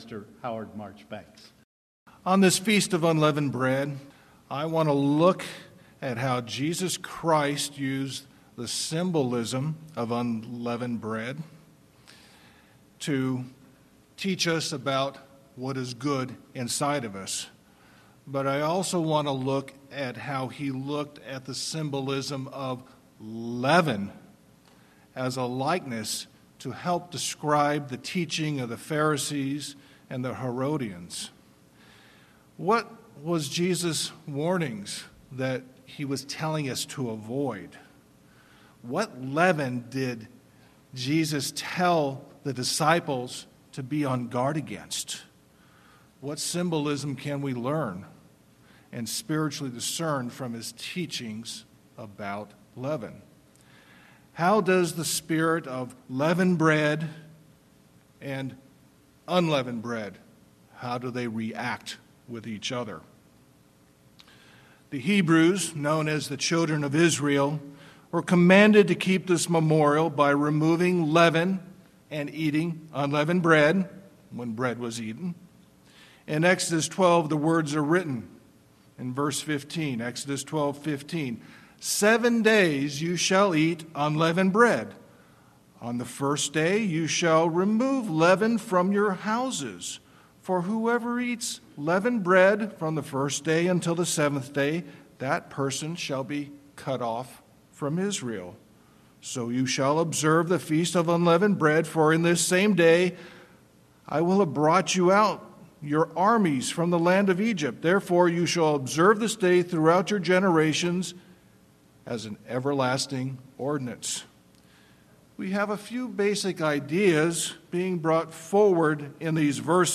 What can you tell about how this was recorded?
First Day of Unleavened Bread 2016